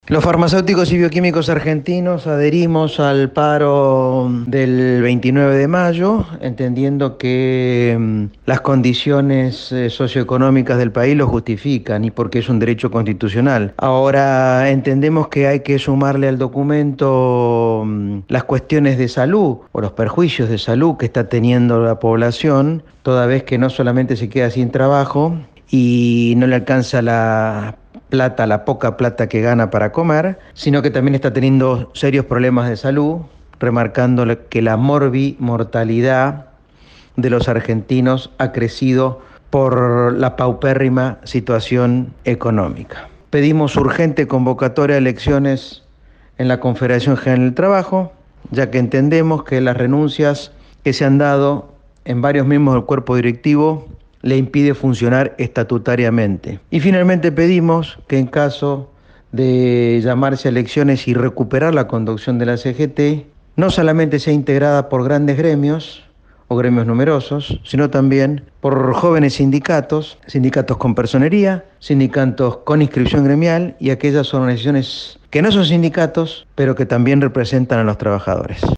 En la biblioteca de Camioneros se llevó adelante el plenario en el que el Frente Sindical debatió y definió adherir al paro general del 29 de mayo, convocado por la Confederación General del Trabajo, en rechazo a la política económica.